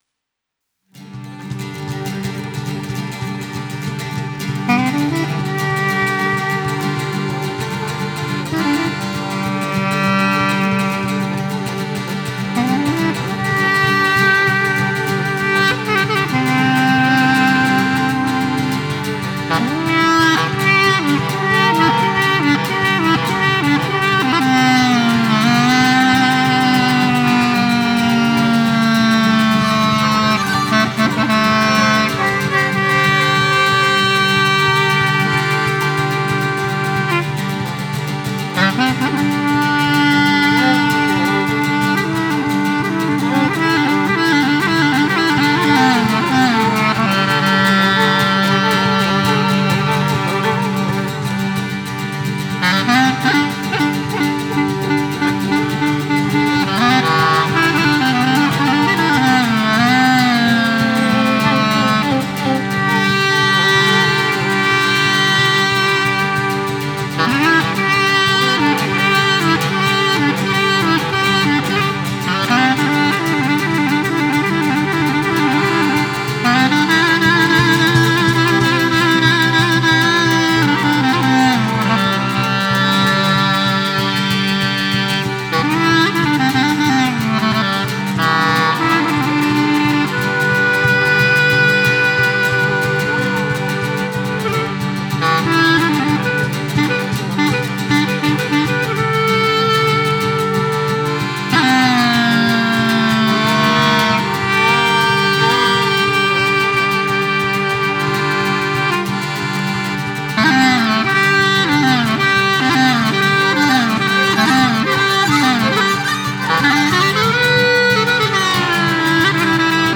Kaba me një pjesë. Një formë e veçantë e strukturimit të kabasë toske. Kjo kaba konturohet si kaba e natyrës baritore përshkruese, e ngjashme me meloditë tradicionale të fyellit dhe kavallit të Toskërisë.
gërnetë
violinë
llautë
fizarmonikë